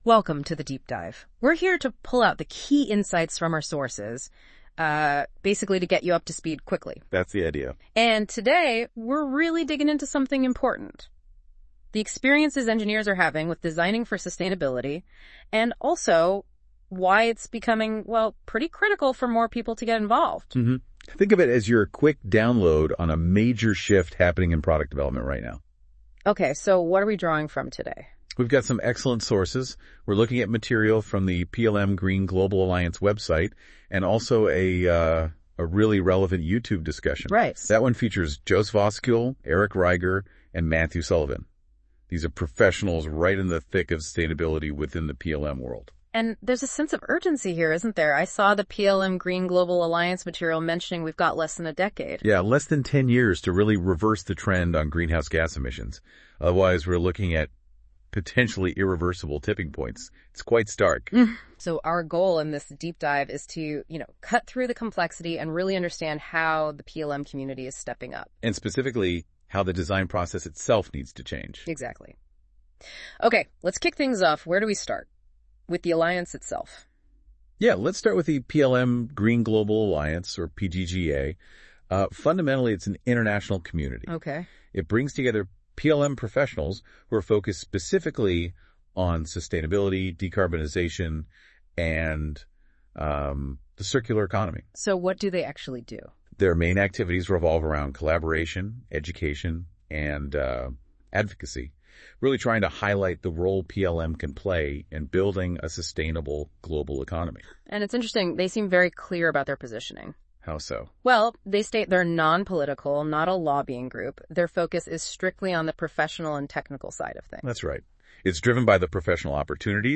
Listen to an AI-assisted audio overview of the PLM Green Global Alliance HERE .